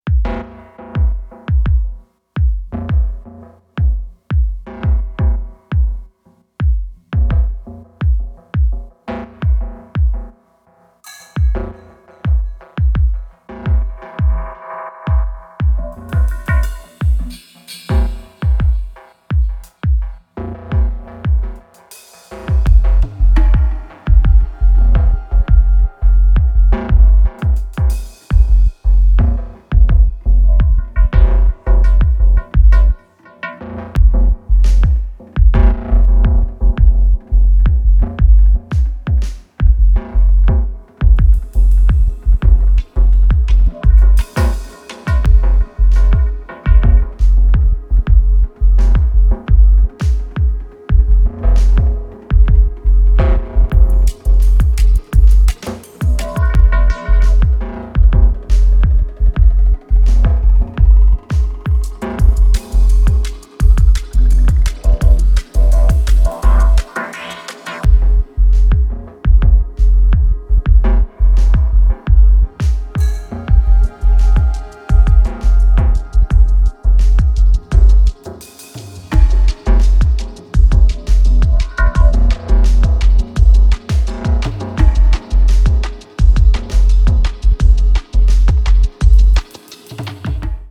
非常に先進的、かつ神聖さすら感じさせるDNB表現を堂々開陳